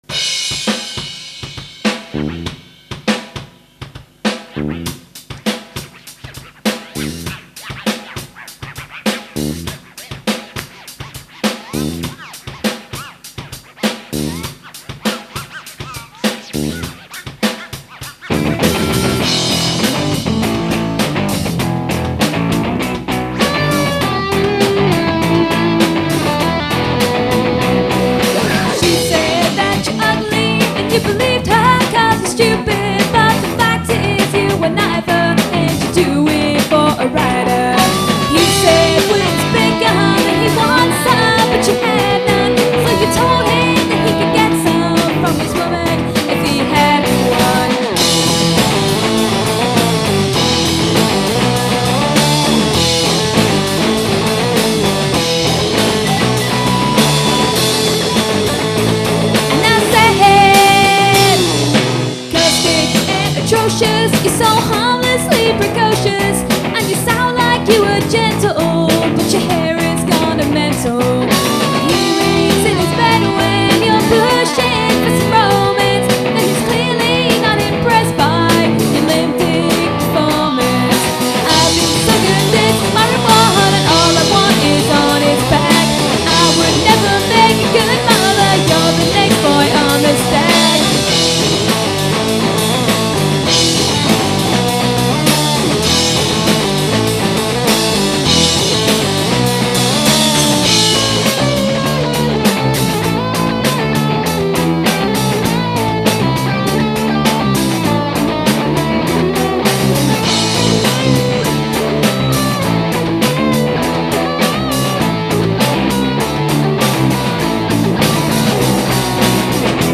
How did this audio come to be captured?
Demo Recordings